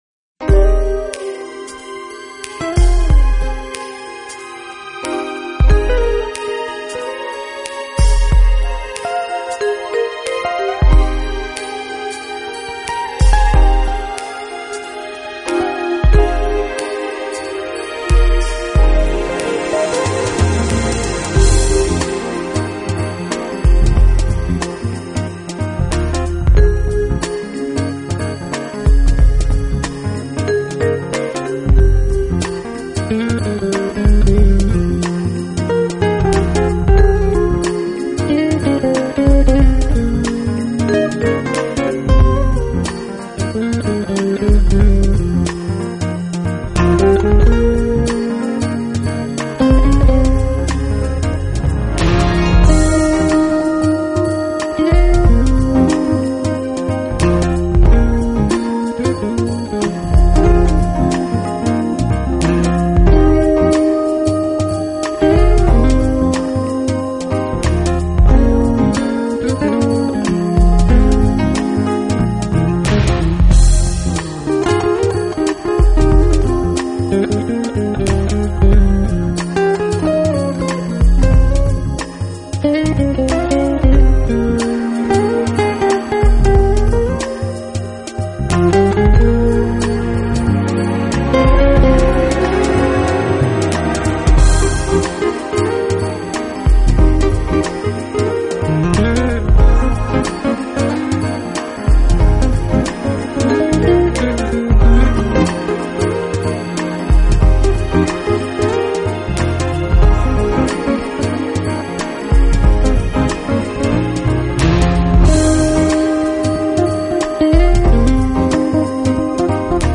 专辑风格：Smooth Jazz